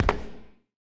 Sfx Dodgeball Bounce Sound Effect
sfx-dodgeball-bounce-1.mp3